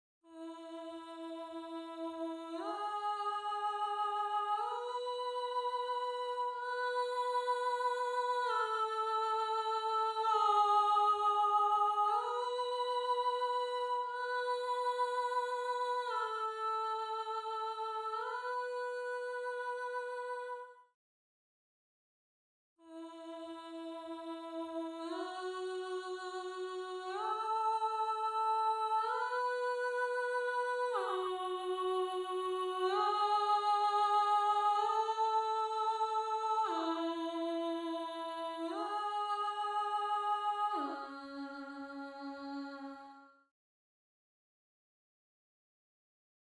3. Voice 3 (Alto/Alto)
gallon-v8sp1-21-Alto_0.mp3